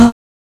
Index of /m8-backup/M8/Samples/Fairlight CMI/IIX/CHORAL